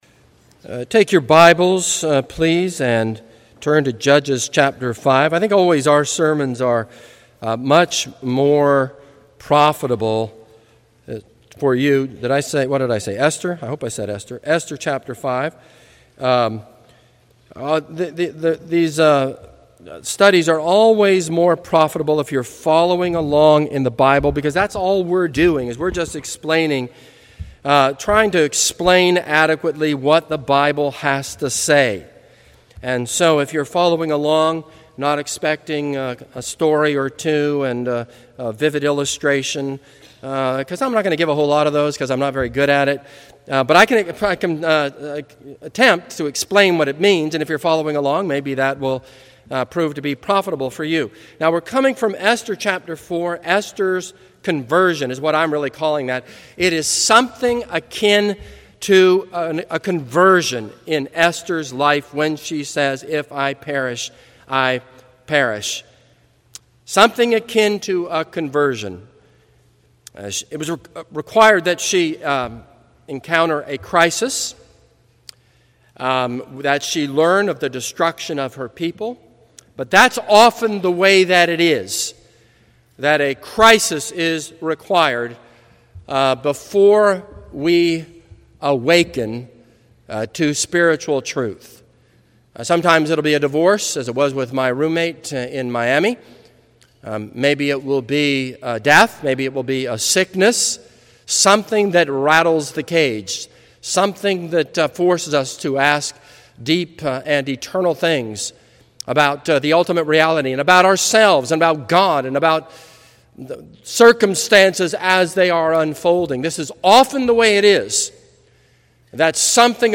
This is a sermon on Esther 5.